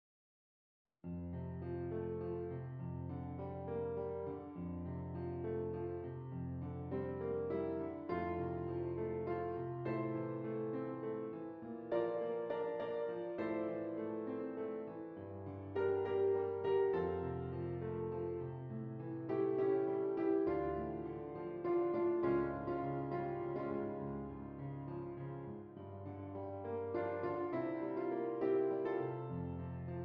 F Major
Andante